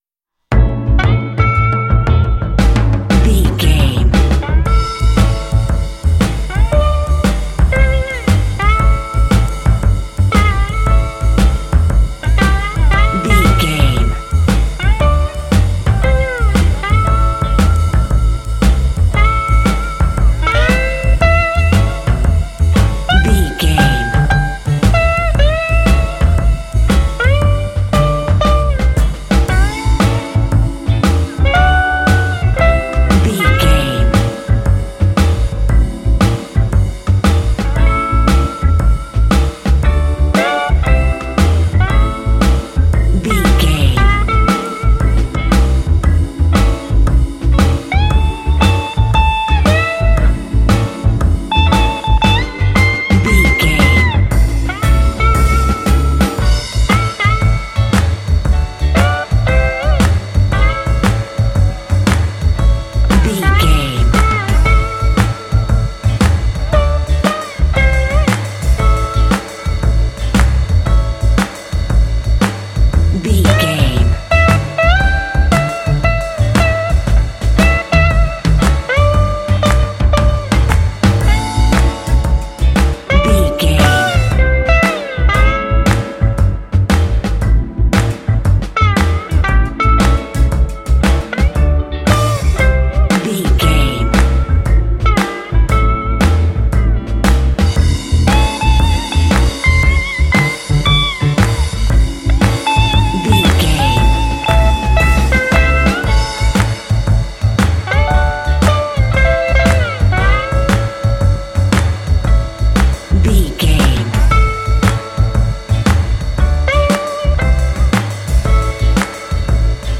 This 12-bar blues track
Uplifting
Aeolian/Minor
groovy
melancholy
bouncy
electric guitar
drums
double bass
electric organ
blues